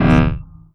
SCIFI_Energy_Pulse_02_mono.wav